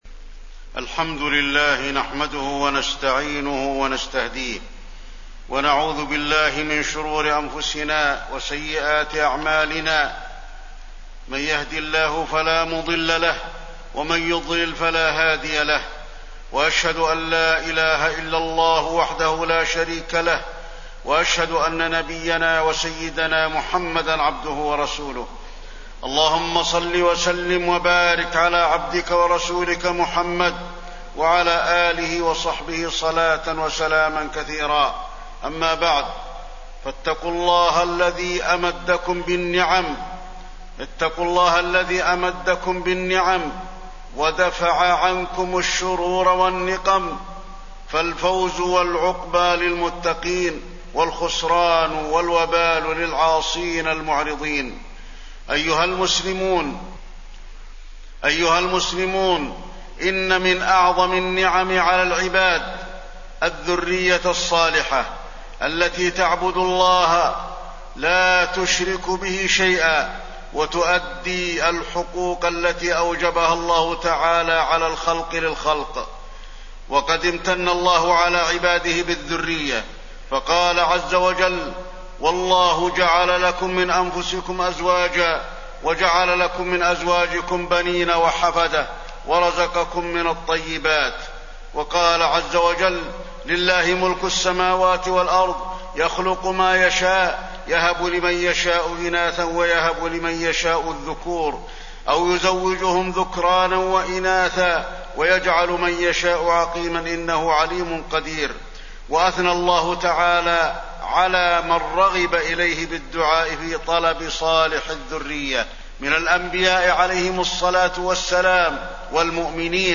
تاريخ النشر ١٣ رجب ١٤٣١ هـ المكان: المسجد النبوي الشيخ: فضيلة الشيخ د. علي بن عبدالرحمن الحذيفي فضيلة الشيخ د. علي بن عبدالرحمن الحذيفي التربية الصالحة